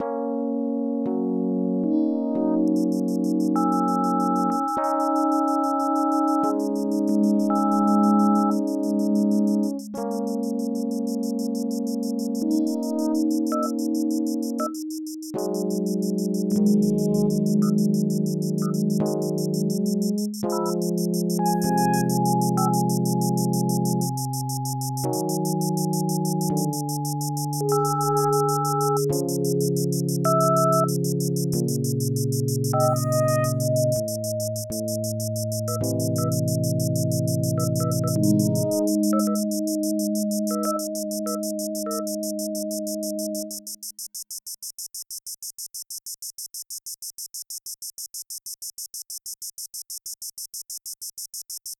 A obra baseia-se no período da quarentena. Por isso, uma atmosfera tensa e o constante som de um celular, aparelho que torna-se fundamental para a manutenção dos afetos, estão presentes na sua quase totalidade.
A tensão presente na música aumenta com o passar do tempo, de maneira análoga com a tensão dos quarentenados. E o fim da música é abrupto sinalizando uma ligação que talvez não tenha se realizado, uma conexão que talvez não tenha sido feita.